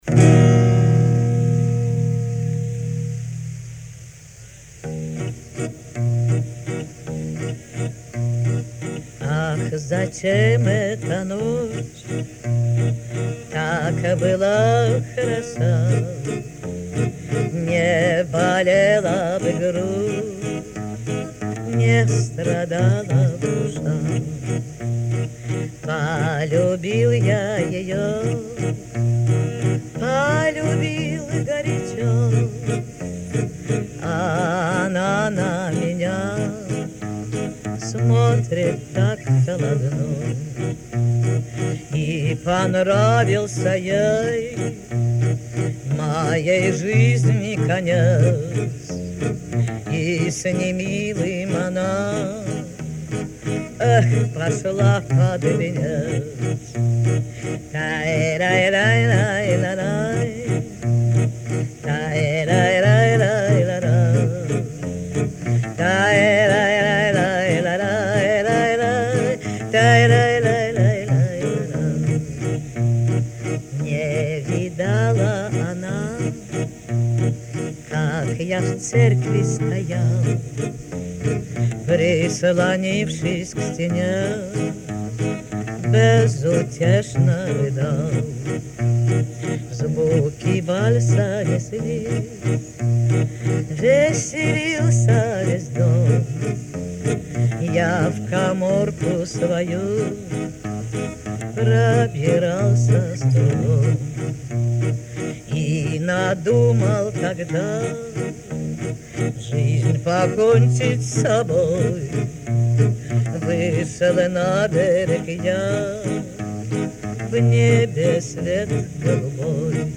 Оригинальный голос!